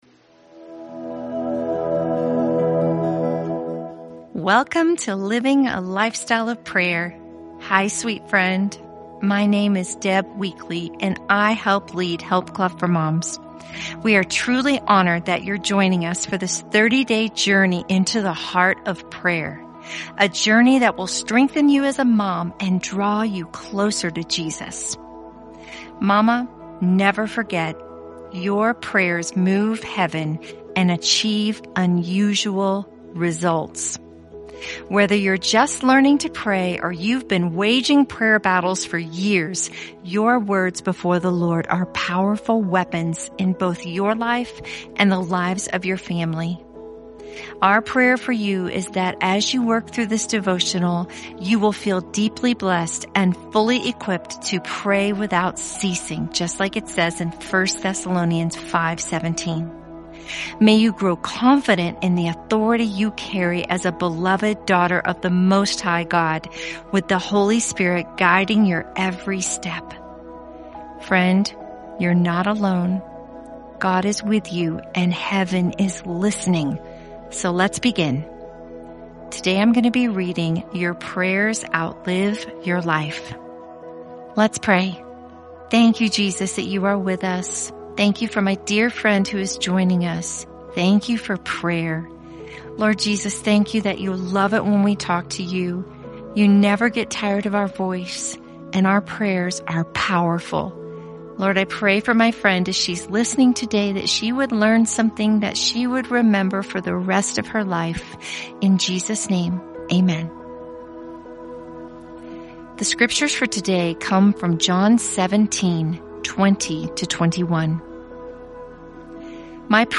Living a Lifestyle of Prayer is a 30-day audio devotional lovingly created by the team at Help Club for Moms: moms just like you, with a heart for Jesus and for moms.